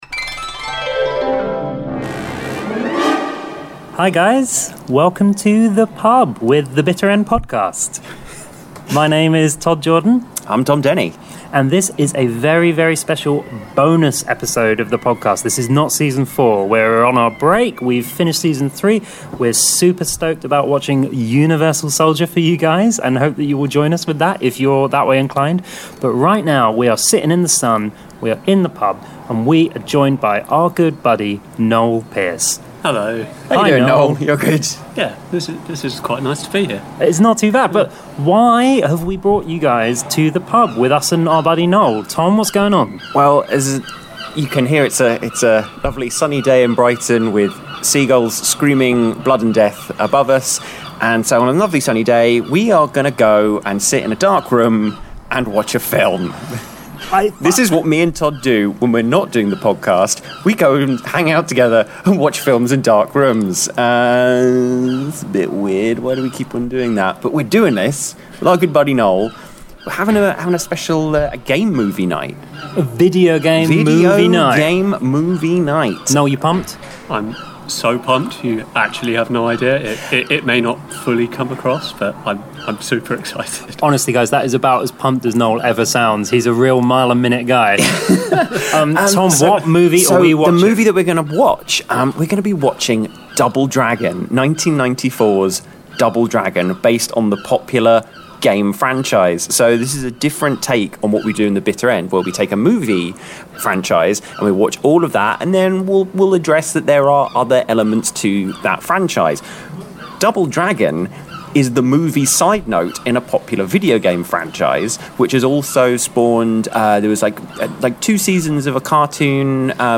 go and watch 1994's Double Dragon movie, and then chat about it on the walk home.